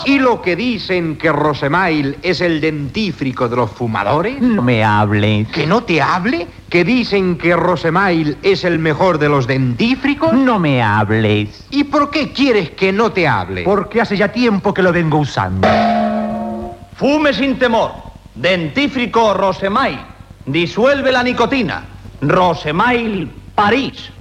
Anunci del dentifrici Rosémail
Extret de Crònica Sentimental de Ràdio Barcelona emesa el dia 8 d'octubre de 1994.